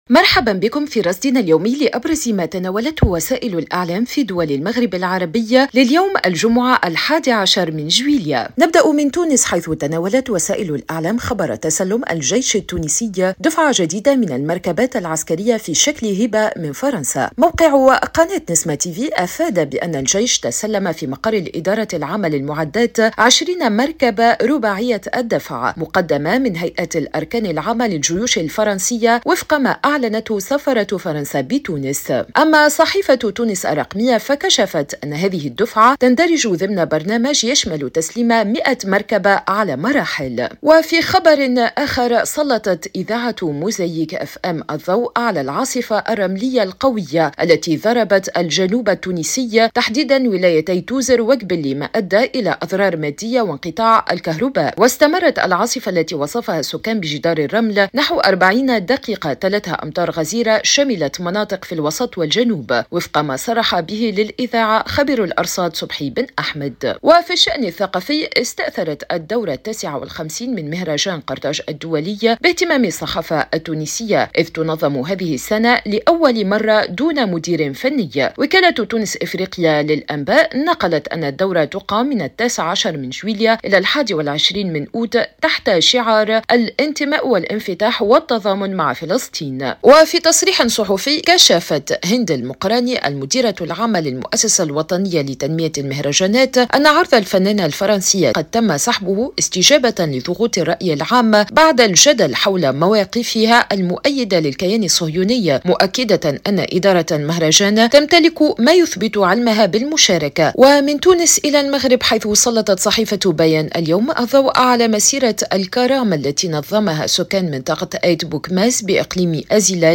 صدى المغرب برنامج إذاعي يومي يُبث عبر إذاعة الشرق، يسلّط الضوء على أبرز ما تناولته وسائل الإعلام في دول المغرب العربي، بما في ذلك الصحف، القنوات التلفزية، والميديا الرقمية.